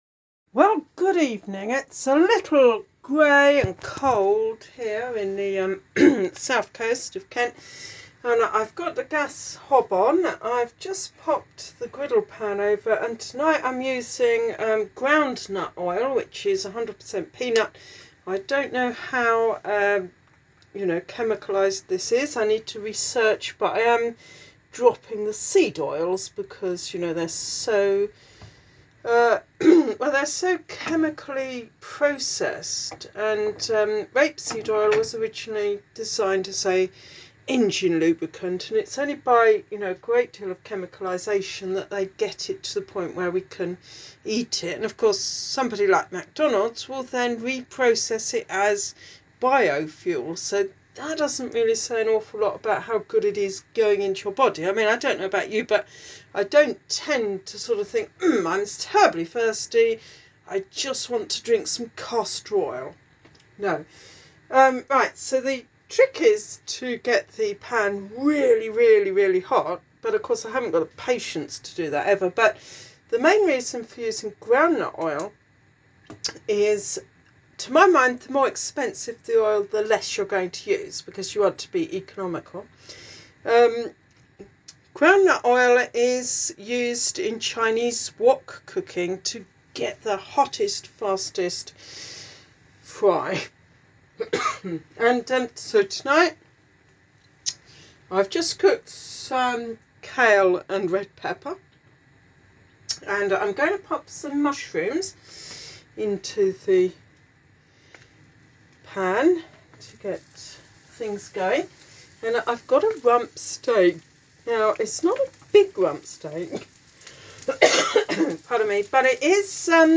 Hear the sizzle ..